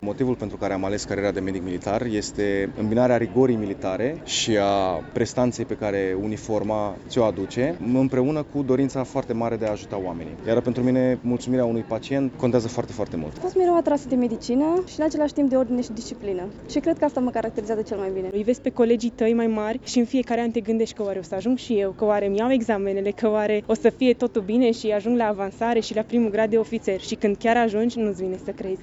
Ceremonia s-a desfășurat în această dimineață, în Cetatea Medievală din municipiu, unde studenții și-au auzit numele însoțit de noua titulatură.
Pentru viitorii medici militari, acordarea primului grad de ofițer este printre cele mai emoționante momente din carieră: